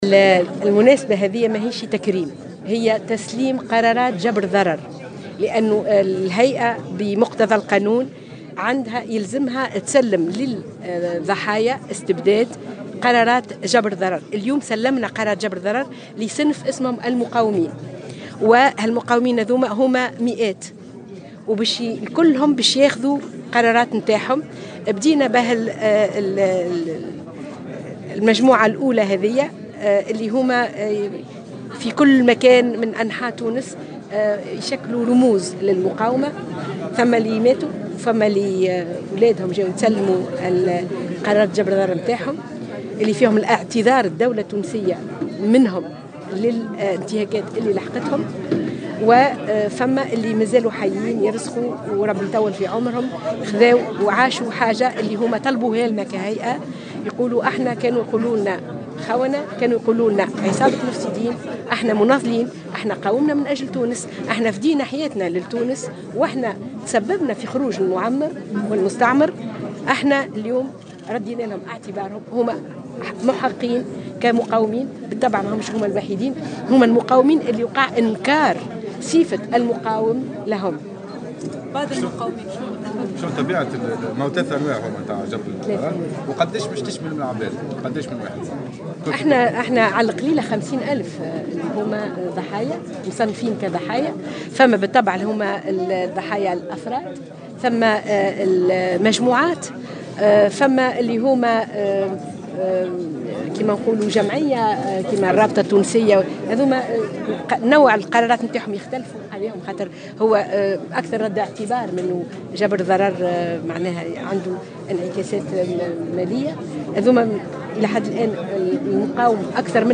وأضافت في تصريح لمراسلة "الجوهرة أف ام" على هامش موكب تسليم أول دفعة من قرارات جبر الضرر لفائدة ضحايا تم ادراجهم ضمن صنف المقاومين ورد الاعتبار إليهم، أن عدد الضحايا لا يقل عن 50 ألف ضحية في واقع الامر إلا أن سيتم جبر الضرر المادي وصرف تعويضات لنحو 20 ألف شخص.